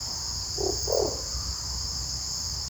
Chaco Owl (Strix chacoensis)
Spanish Name: Lechuza Bataraz Chaqueña
Location or protected area: Parque Provincial Pampa del Indio
Condition: Wild
Certainty: Photographed, Recorded vocal
Lechuza-bataraz-chaquena_1.mp3